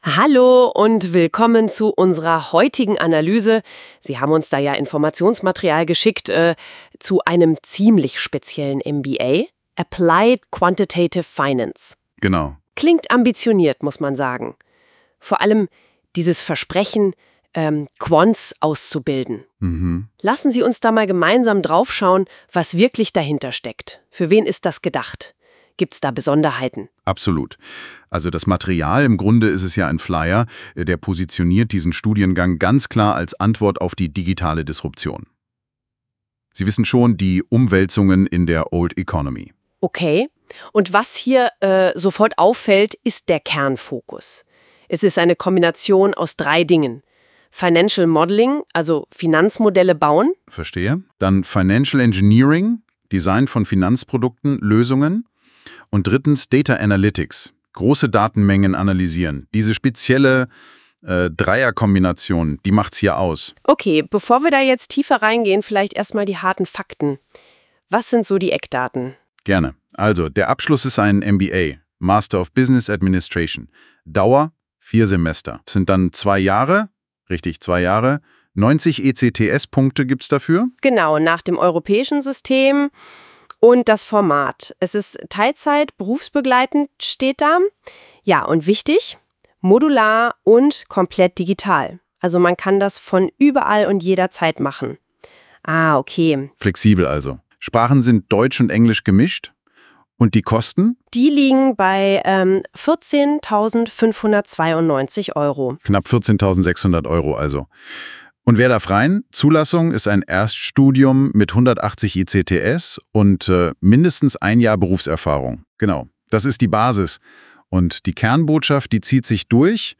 • Die vorliegende Audiodatei wurde unter zu Hilfenahme von künstlicher Intelligenz erstellt.